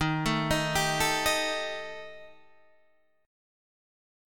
D# Major Flat 5th